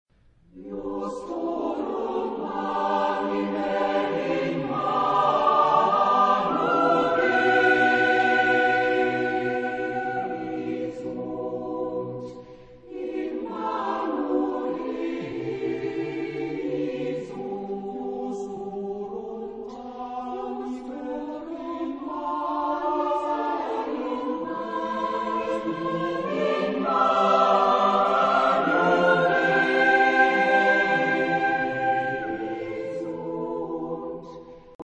Genre-Style-Form: Motet ; Cycle ; Sacred
Type of Choir: SSATTBB  (7 mixed voices )
Tonality: G major
sung by Neuruppiner A Cappella Chor
Discographic ref. : 7. Deutscher Chorwettbewerb 2006 Kiel